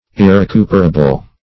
Search Result for " irrecuperable" : The Collaborative International Dictionary of English v.0.48: Irrecuperable \Ir`re*cu"per*a*ble\, a. [L. irrecuperabilis: cf. OF. irrecuperable.
irrecuperable.mp3